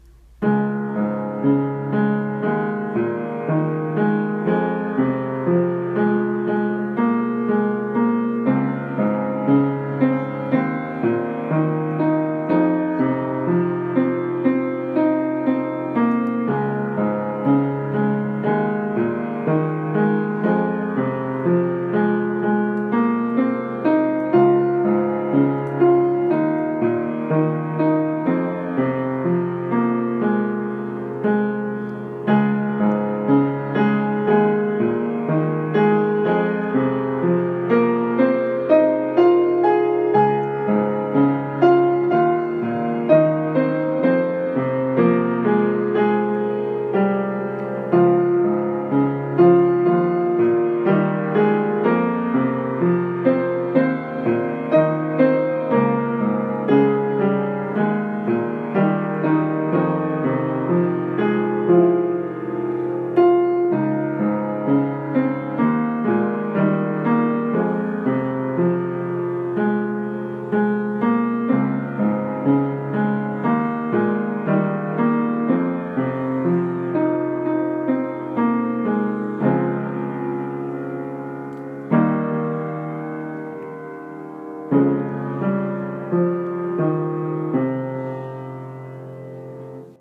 声劇＊夢＊台本 nanaRepeat